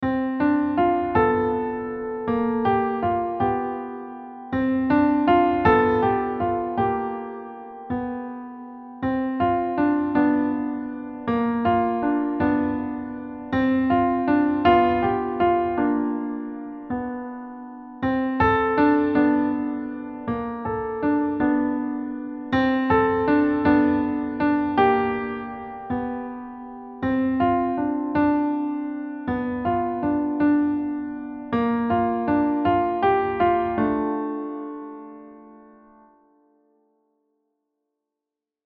Key / Hand positions: RH in D minor pentascale; LH Middle C
Time signature: 3/4 (felt like a waltz in “1”), BPM ~160